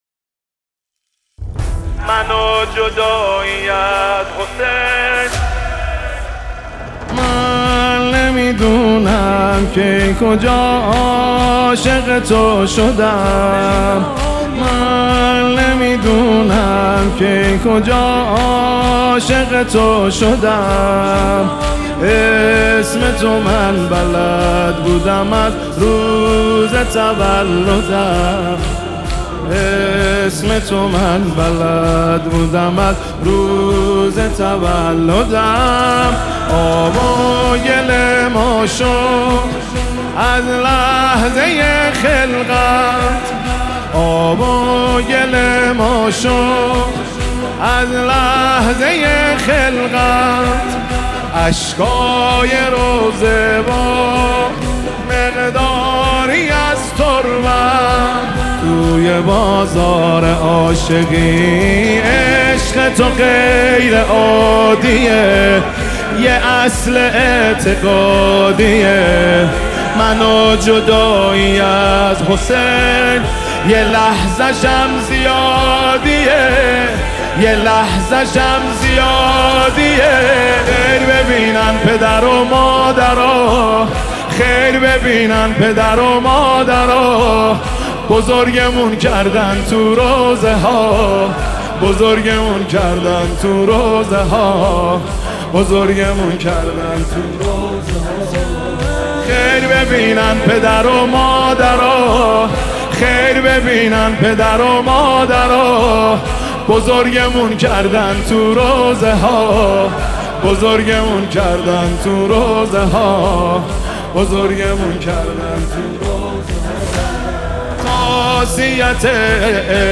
دانلود نوحه با کیفیت 320 دانلود نوحه با کیفیت 128